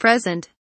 present kelimesinin anlamı, resimli anlatımı ve sesli okunuşu
(bu anlamda “prizent” diye okunur)